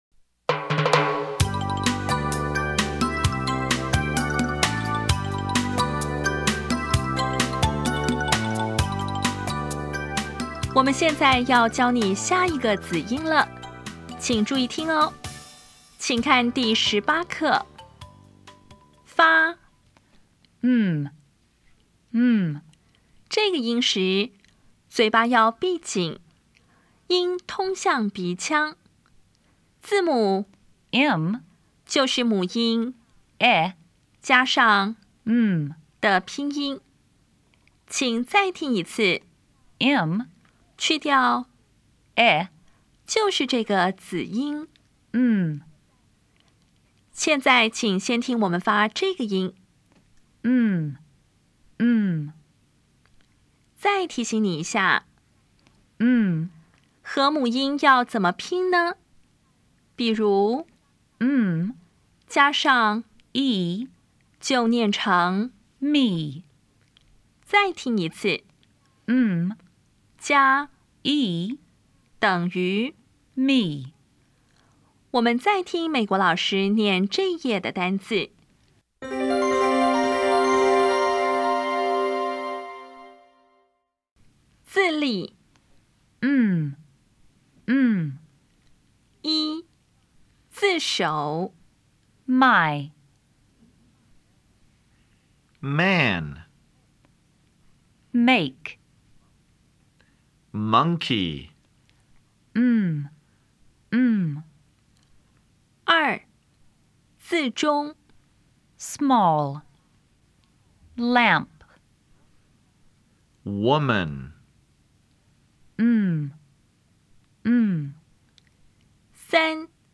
当前位置：Home 英语教材 KK 音标发音 子音部分-2: 有声子音 [m]
音标讲解第十八课
[maɪ]
[mæn]